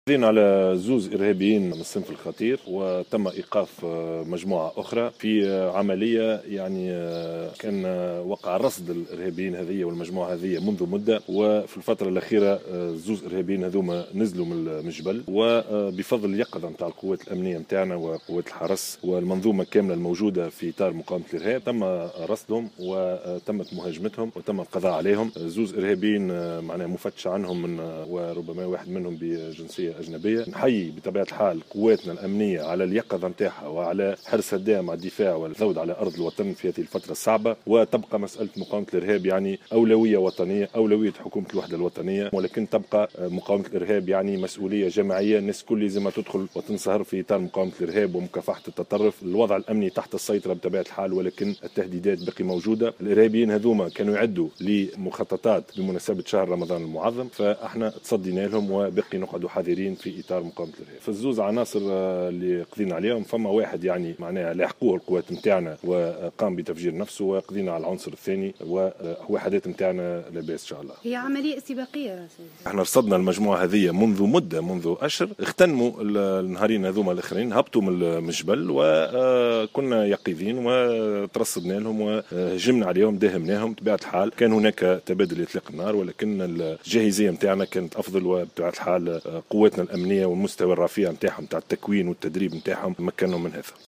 Le chef du gouvernement, Youssef Chahed a indiqué, aujourd’hui, dimanche 30 avril 2017, à Jawhara Fm, que les terroristes neutralisés à Sidi Bouzid étaient en train de planifier des attaques terroristes durant le mois de Ramadan.